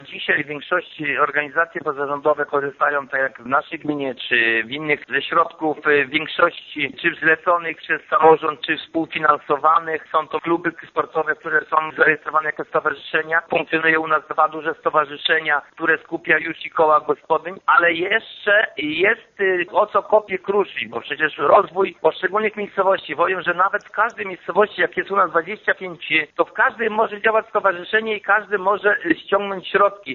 To jeden z najważniejszych wniosków z ogólnopolskiej konferencji, która w piątek 19 marca odbyła się w Woli Okrzejskiej w gminie Krzywda.
Zdaniem wójta Jerzego Kędry im więcej będzie organizacji pozarządowych tym więcej środków będą mogły razem z samorządami pozyskać z Unii Europejskiej: